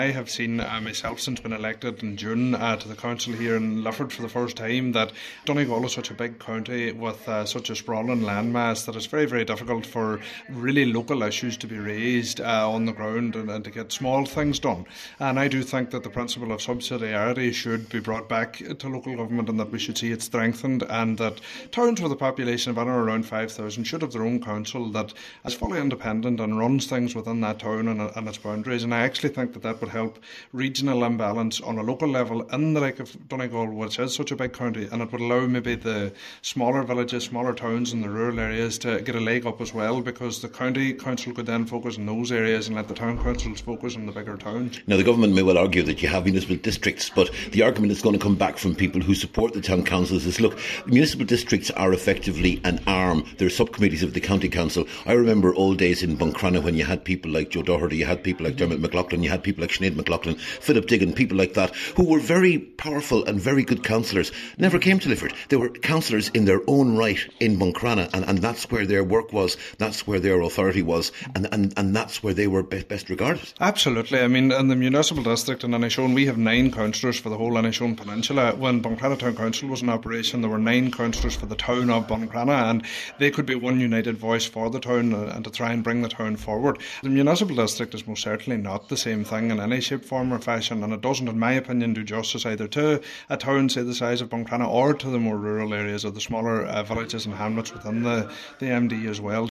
Cllr Bradley says if councils are restored to larger towns and given their own budgets, that will free up more resources for other areas: